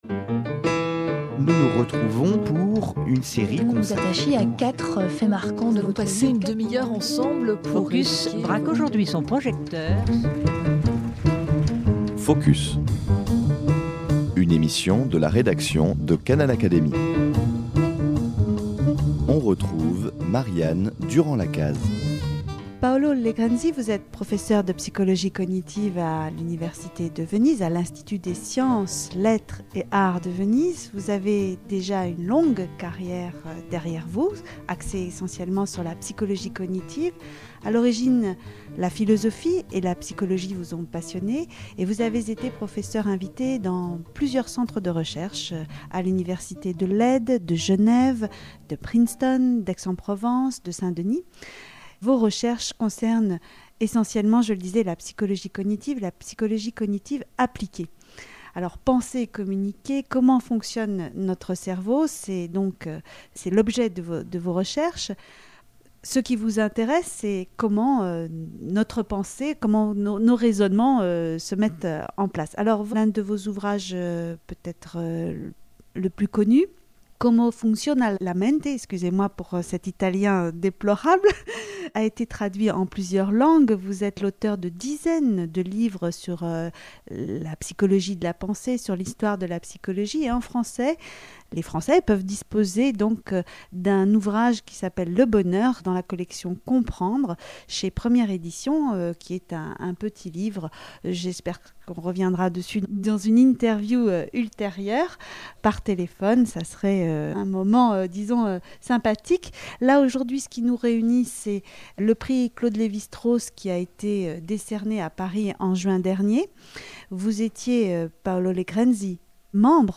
Canal Académie a rencontré à l’Institut des sciences, des lettres et des arts de Venise